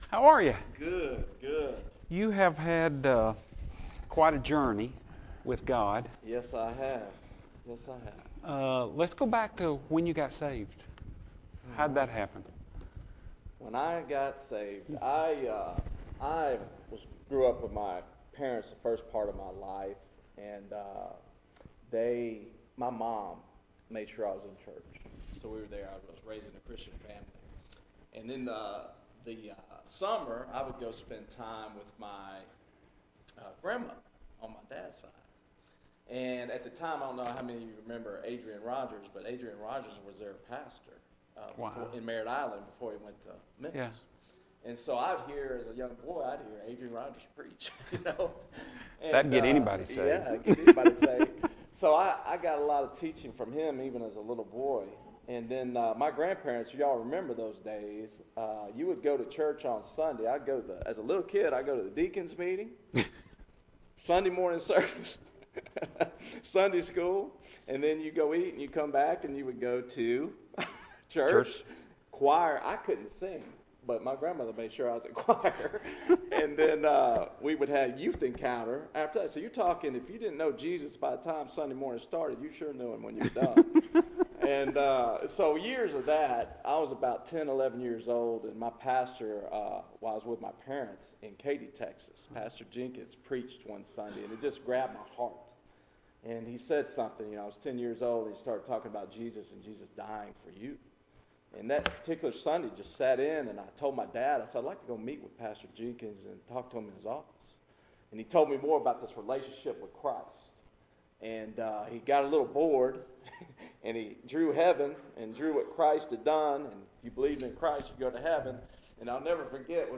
Audio Sermon Only